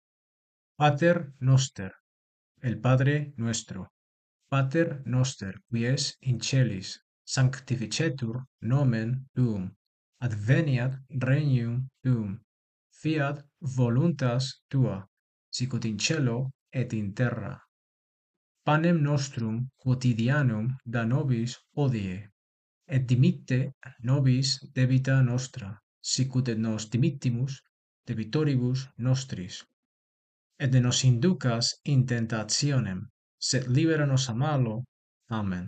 (Descarga el audio de cómo pronunciar el Pater Noster)